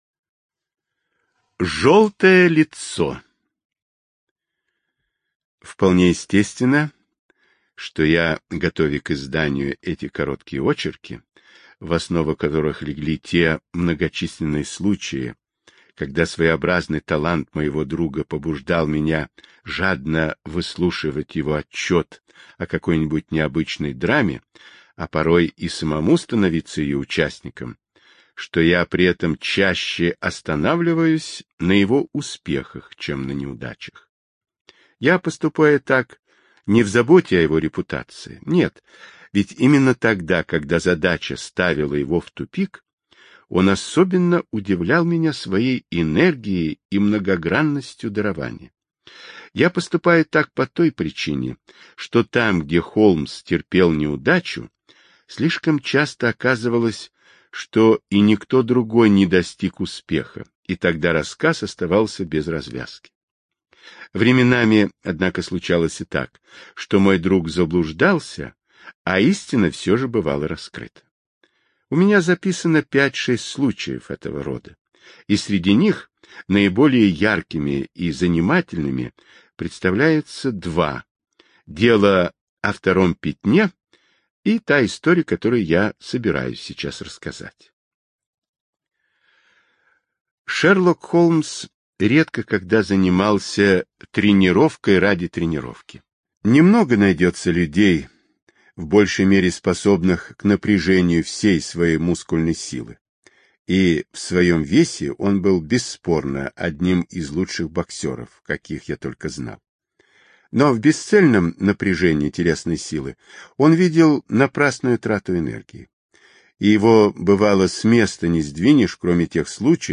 Жёлтое лицо — слушать аудиосказку Артур Конан Дойл бесплатно онлайн